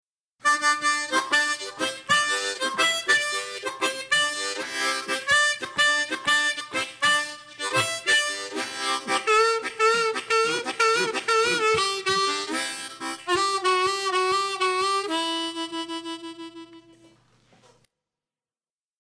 La base musical que encontrarás en la clase es en tonos MI menore y tocaremos una armónica en C tocando en quinta posición.
TÉCNICA 6: INSISTENCIA. Se toman una o más notas y se las repiten variando el sentido rítmico.